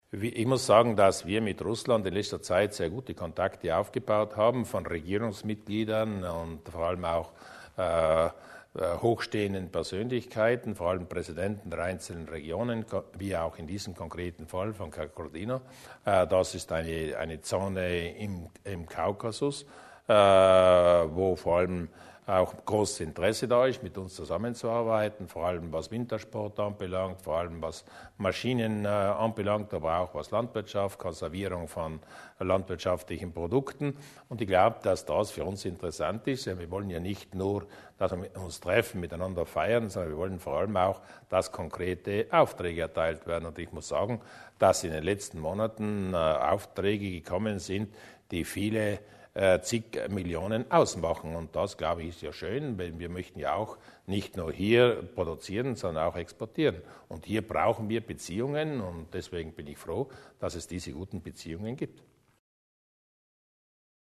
Landeshauptmann Durnwalder zum Besuch der russischen Delegation